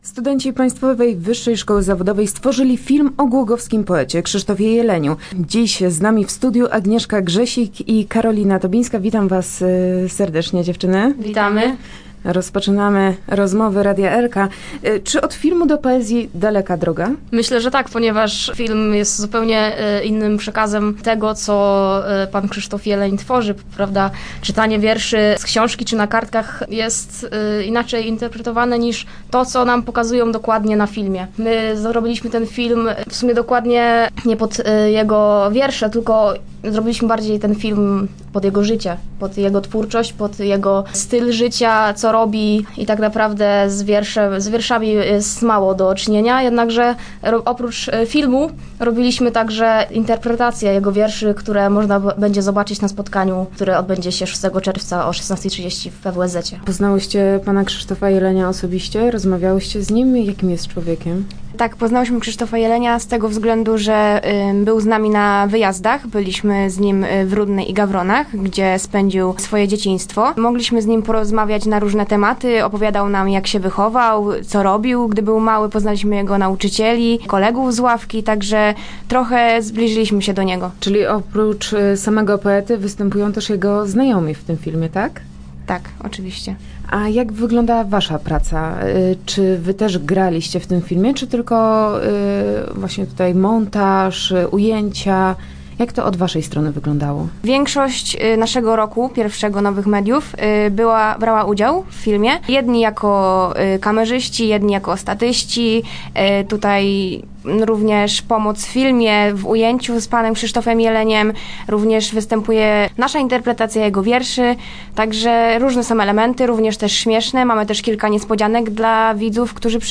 Start arrow Rozmowy Elki arrow Film o głogowskim poecie